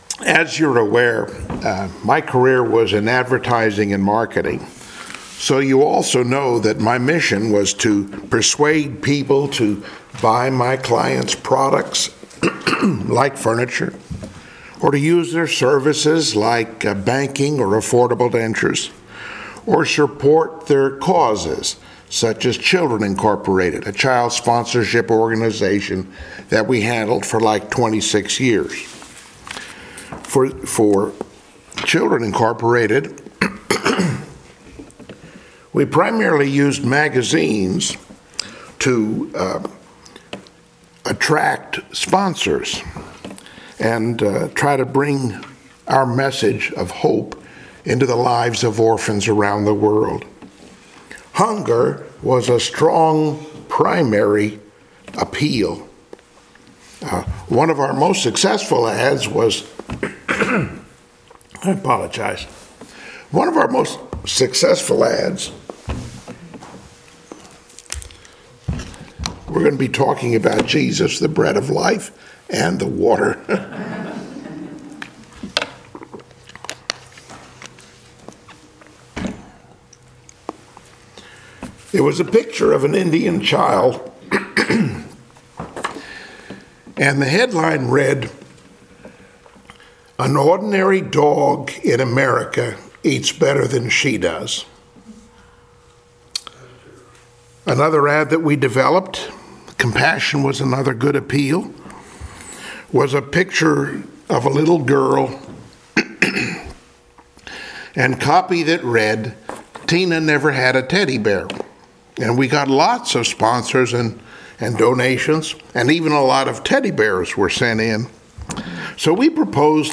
Phillipians 4:10-13 Service Type: Sunday Morning Worship Topics